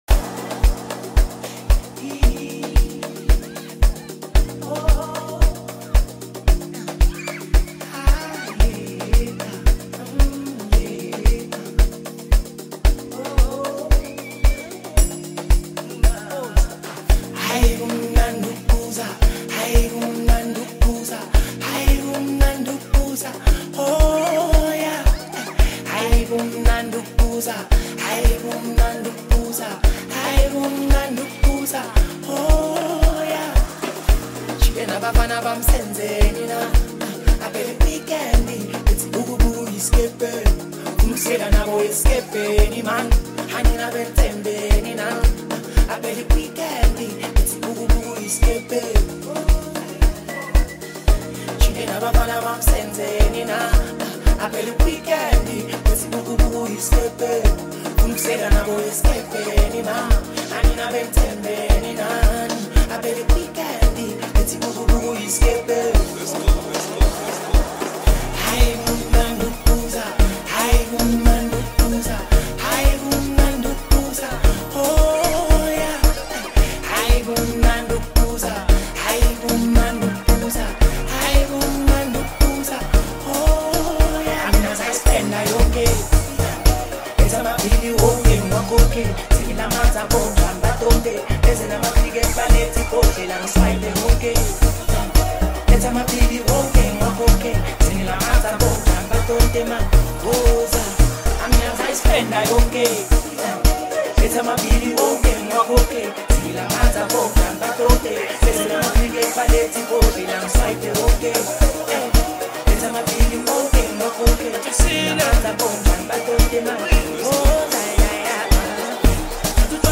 is a groovy anthem